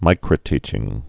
(mīkrə-tēchĭng)